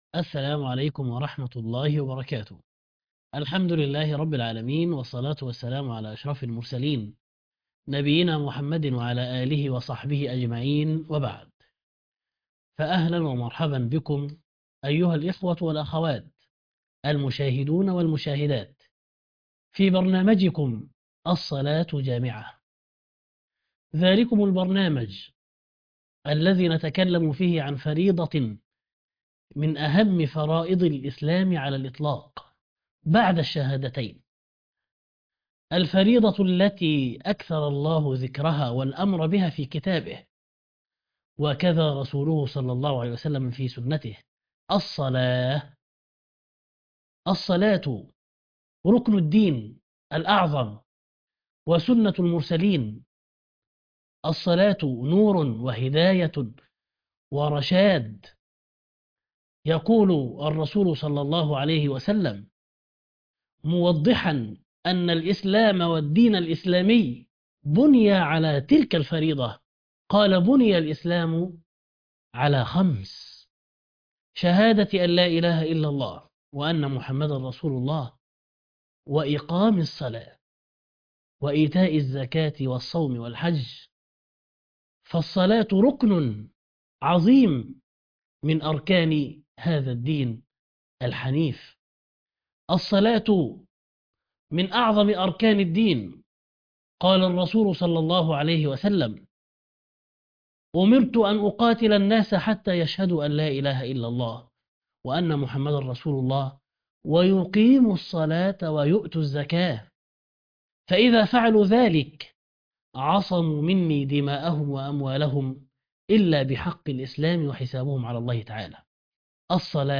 اللقاء 375 تفسير سورة النساء - وعيسي وايوب ويونس ... (6/10/2024) التفسير - فضيلة الشيخ محمد حسان